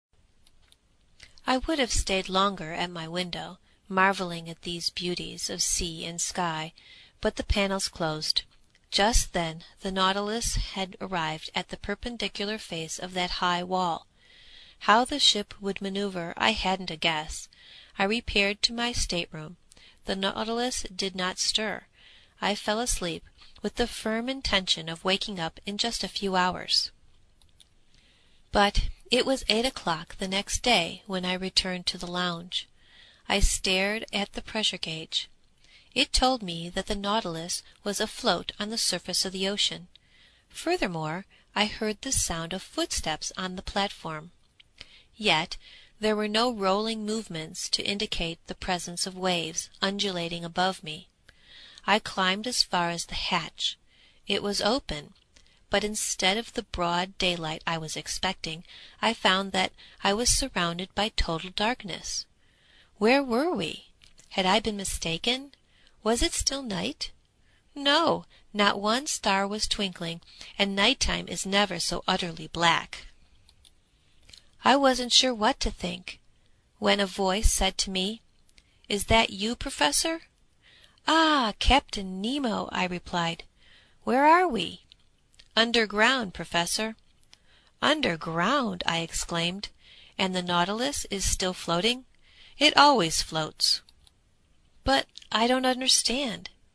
英语听书《海底两万里》第396期 第25章 地中海四十八小时(22) 听力文件下载—在线英语听力室
在线英语听力室英语听书《海底两万里》第396期 第25章 地中海四十八小时(22)的听力文件下载,《海底两万里》中英双语有声读物附MP3下载